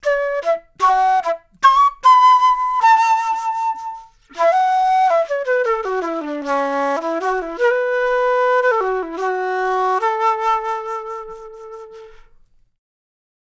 Flute One Shot 01.wav